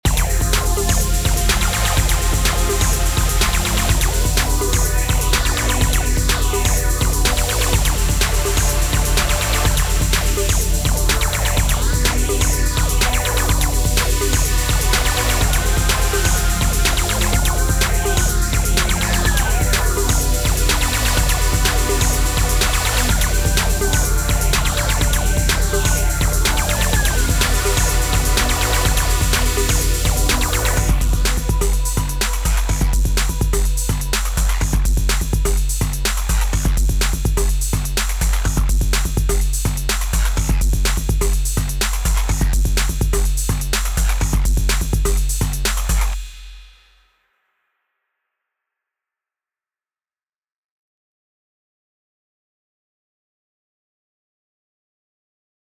Несколько лет назад начал делать трек, забросил, сейчас вернулся, осталась только демка, а исходники улетели в покойное хранилище Splice, и копий на диске не осталось :(
Так вот, все синты были сделаны в Serum, более менее восстановил остальные дорожки, а одну никак не подобрать.
Не силён в терминологии как звук называется, там такой пиу-пиу есть, как лазер, и как водичка что-ли, он сделан был из какого-то acid баса (вроде как), скорее всего сверху лежали эффекты типа какого-то дисторшена из набора d16 Group. больше ничего не помню.
space_electro.mp3